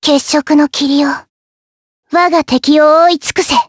Lewsia_A-Vox_Skill4_jp_b.wav